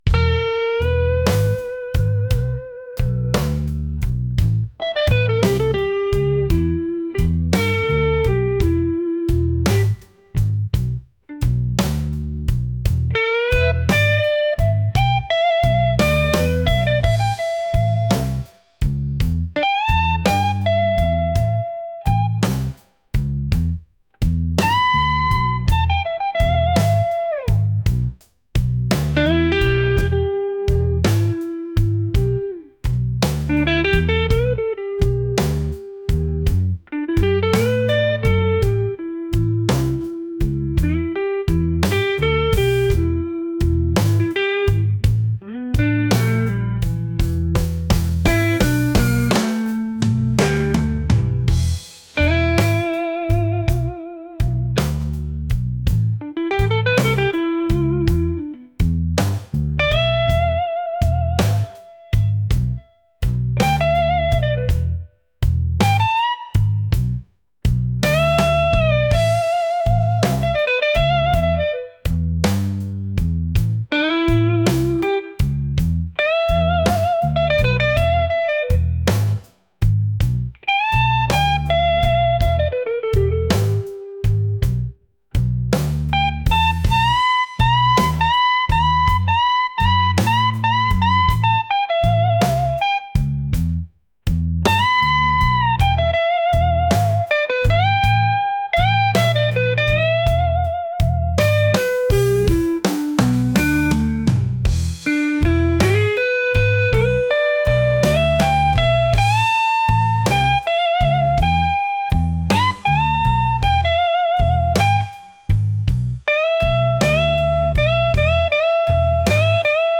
blues | soulful